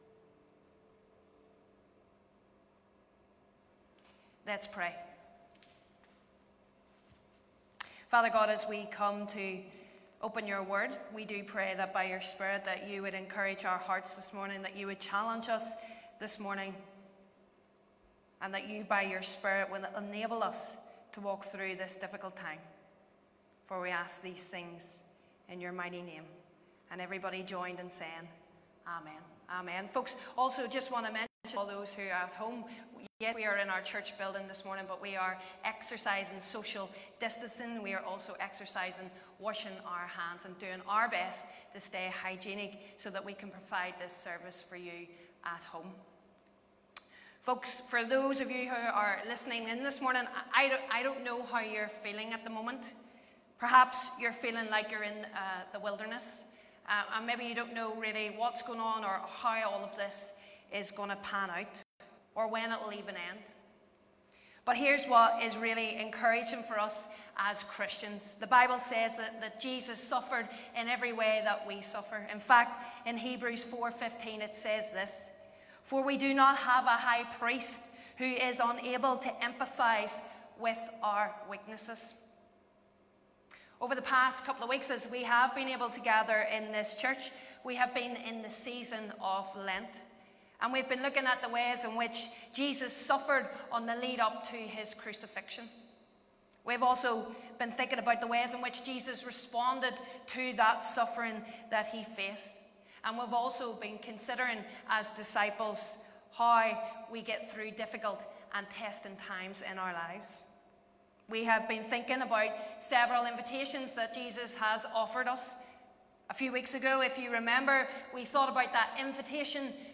22nd-March_Sermon-Audio.mp3